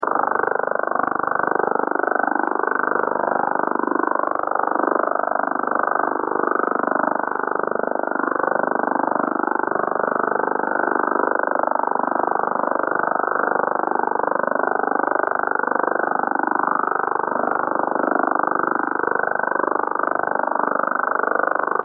The OHR seemed to be centered on 10121.5 kHz, but spreading over about 15 kHz.
That Over The Horizon RADAR signal was amazing. S9 here and 20 kHz wide.